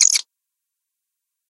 PixelPerfectionCE/assets/minecraft/sounds/mob/silverfish/say3.ogg at mc116